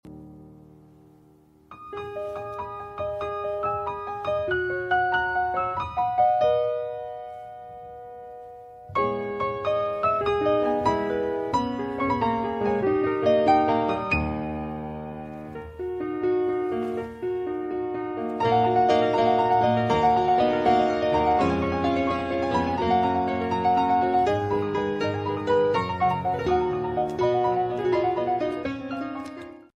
the piano sound